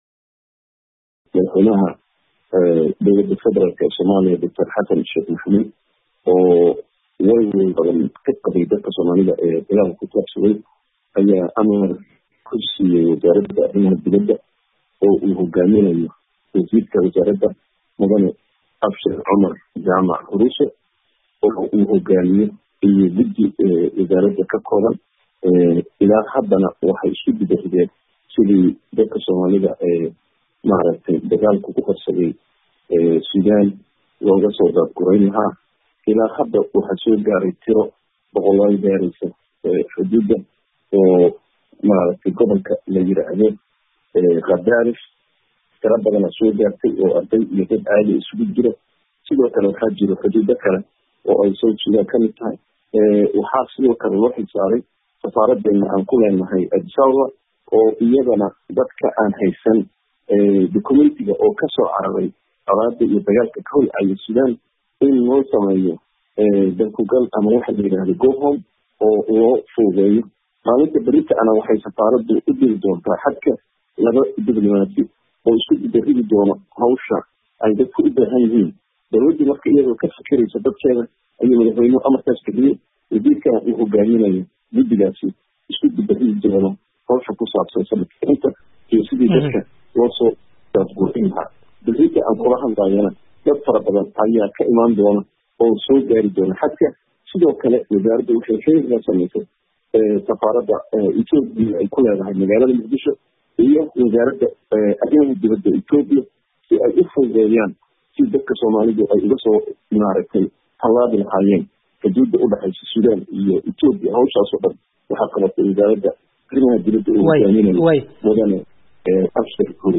Wareysi: Dowladda Soomaaliya oo sheegtay inay ardaydii ugu horreysay kasoo saartay dalka Sudan
khadka telefoonka kula xiriiray danjire Diinaari oo ah isku-xiraha guddiga ay arrinta dowladdu u xil-saartay.